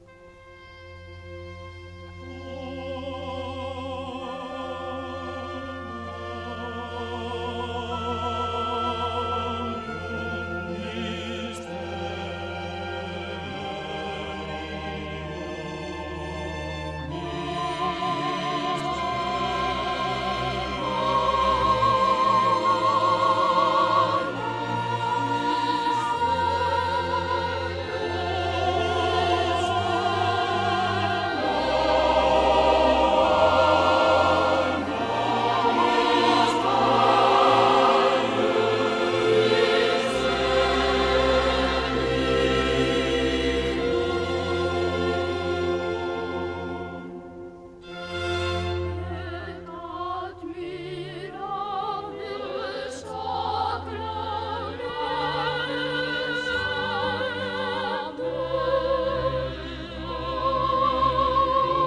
擔綱的是巴塞隆納室內合奏團與Montserrat修道院詩班，
演出相當有味道，只是母帶受損而使音質受到些許影響，稍感可惜。